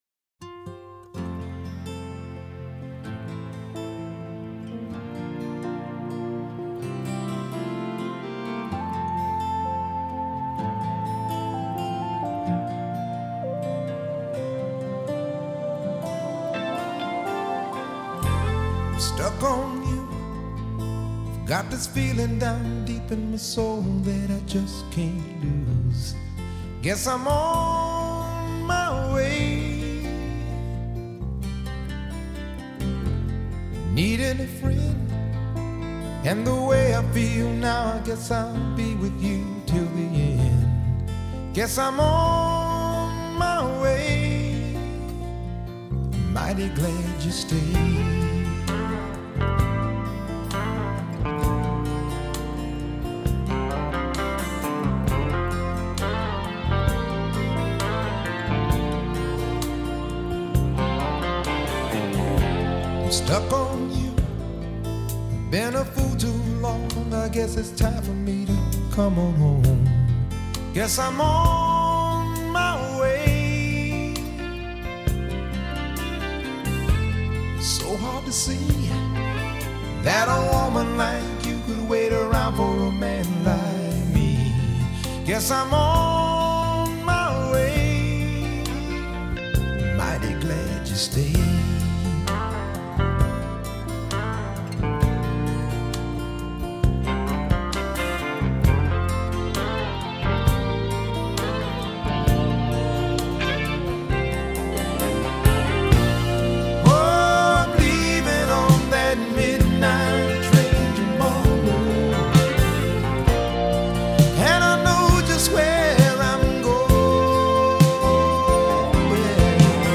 Genre: Pop
Acoustic Guitar